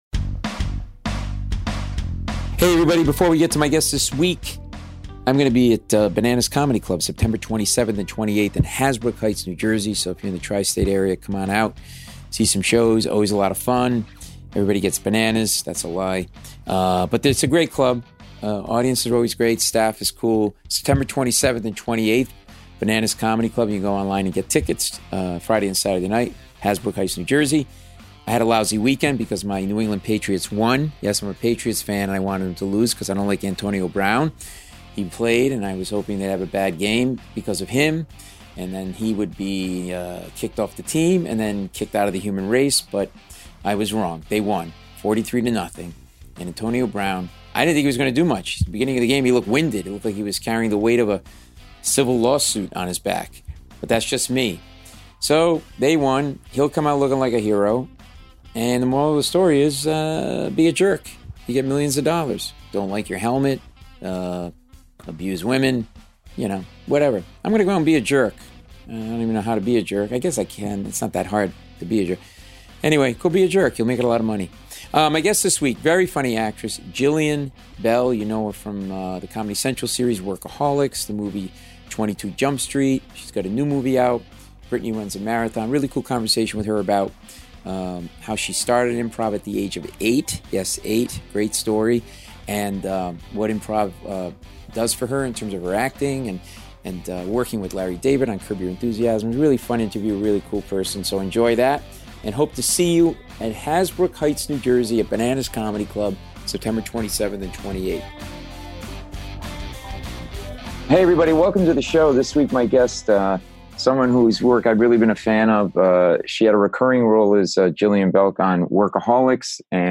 Hear Officer Fanone's first hand account of what he and his fellow officers went through on January 6th in defending the Capitol.